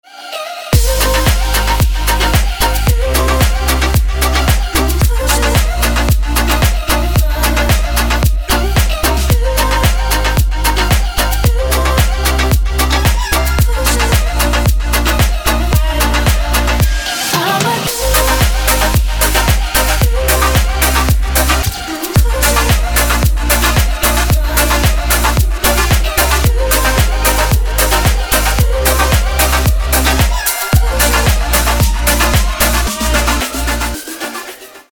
• Качество: 224, Stereo
dance
Electronic
EDM
club